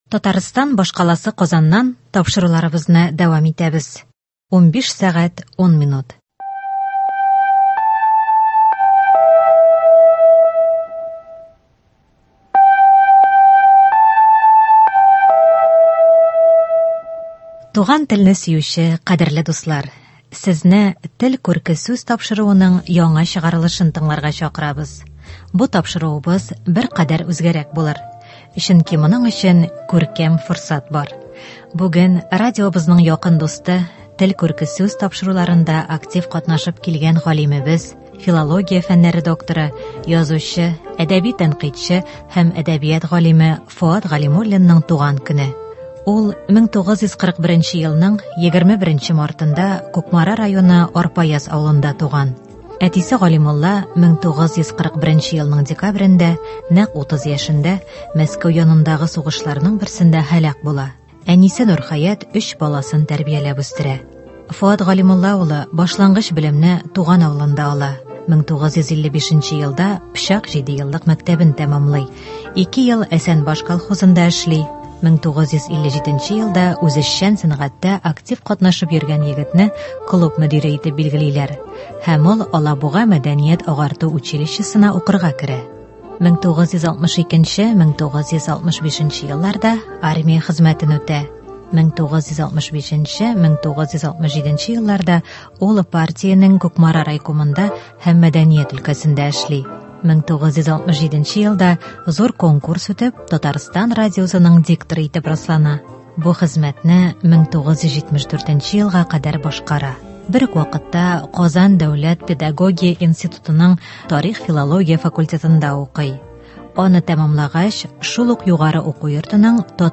Бүген ул безнең кунагыбыз.